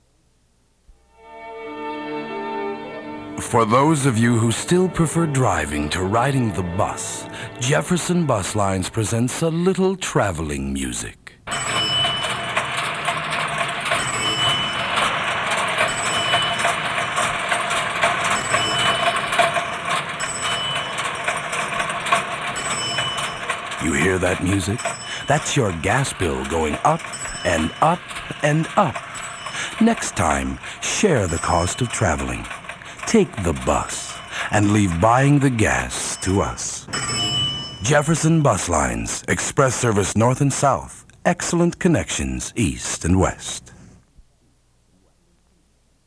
Jefferson Lines radio spot, bus vs. car, undated. 1 master audio file (46 seconds): WAV (3.8 MB) and 1 user audio file: MP3 (823 KB).